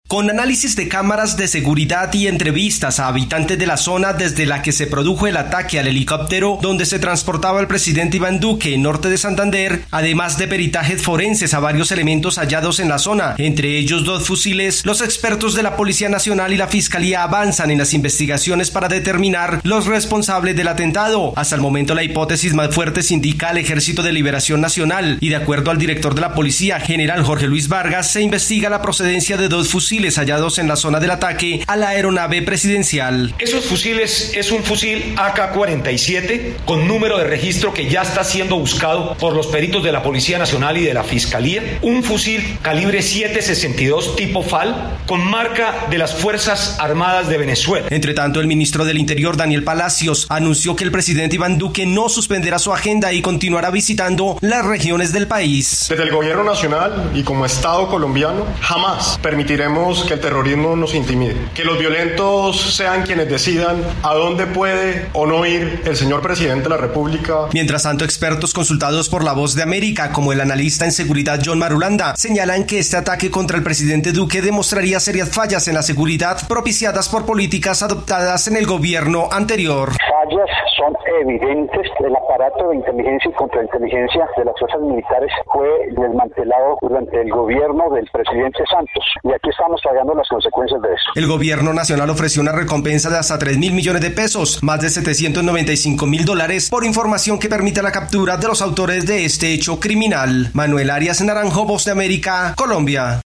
Autoridades colombianas dicen que el ELN estaría detrás del atentado contra el presidente, Iván Duque y hay cinco sospechosos detenidos. Desde Colombia informa el corresponsal de la Voz de América